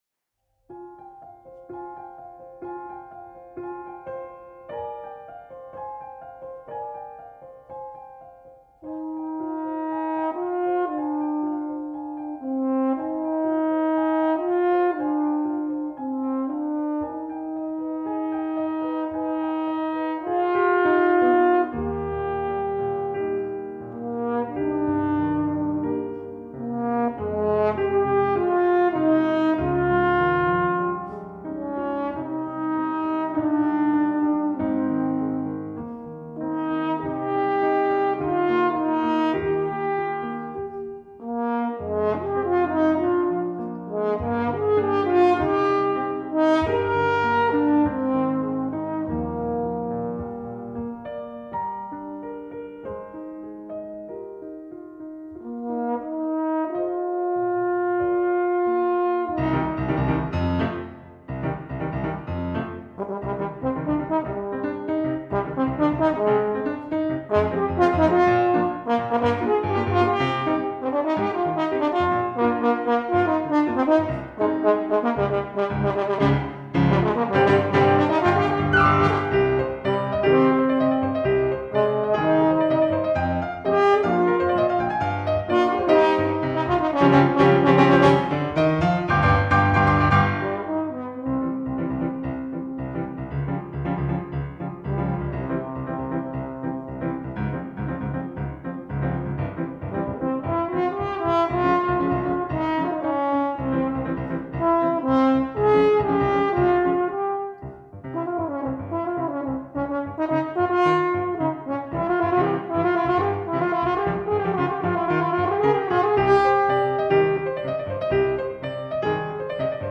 Gattung: für Horn und Klavier